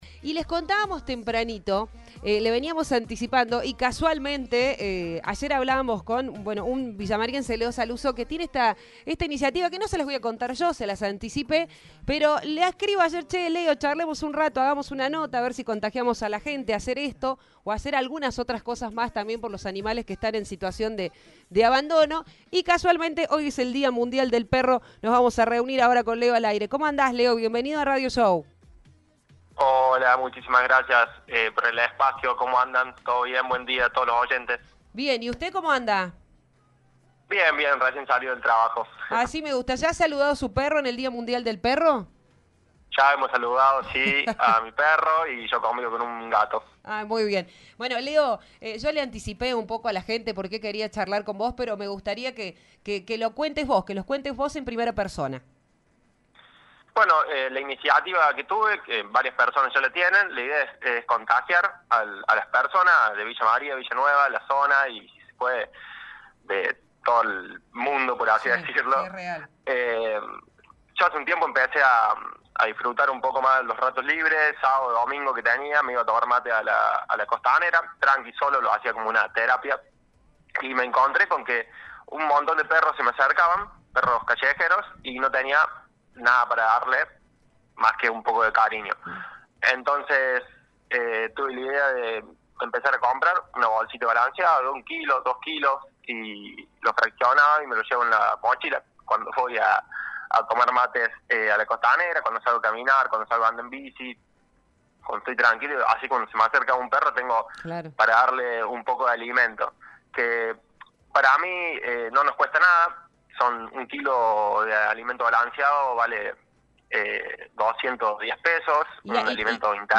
La contó en la entrevista que realizó con nuestro medio.